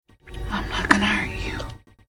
Category: Movies   Right: Personal
Tags: Splice Splice Movie clips Splice sound clips Sci-FI Thriller